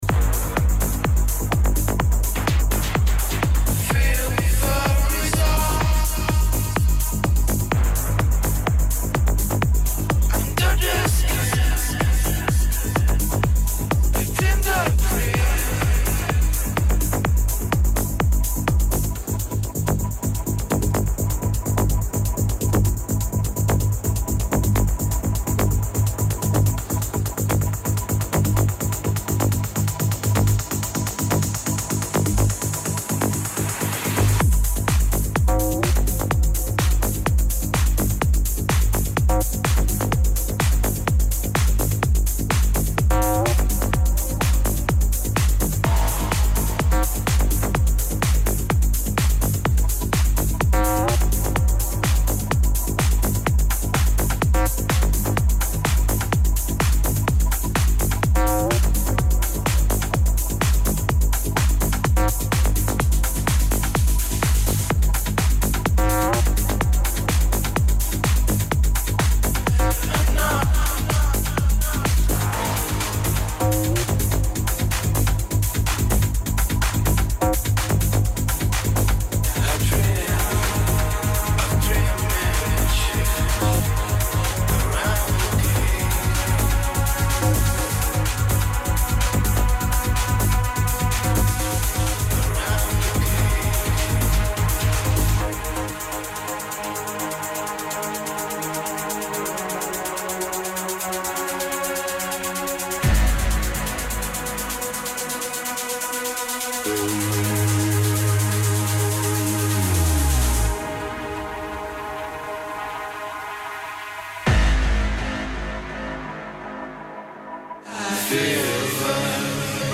In questo podcast di 4 ore troverete i dj set